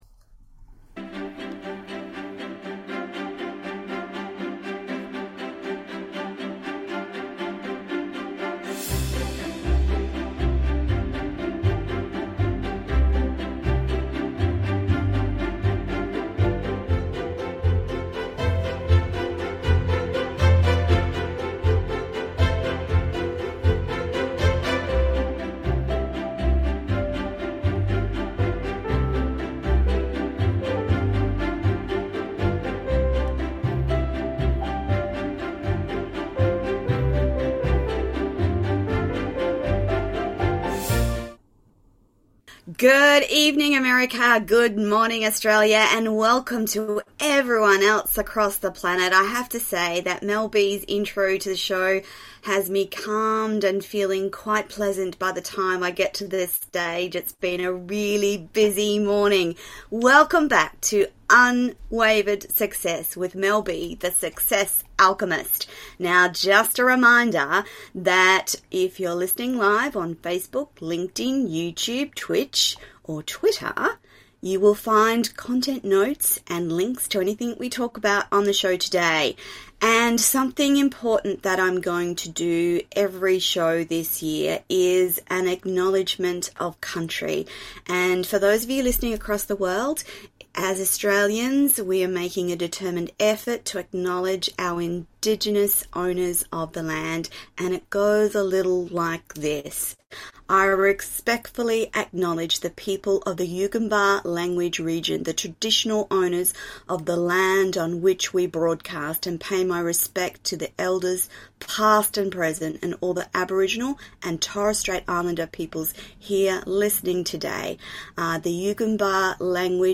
How to realize you’re living with limitations (Just a guide we want this to be an organic free-flowing conversation, one empath to another) Is being empathic a limitation?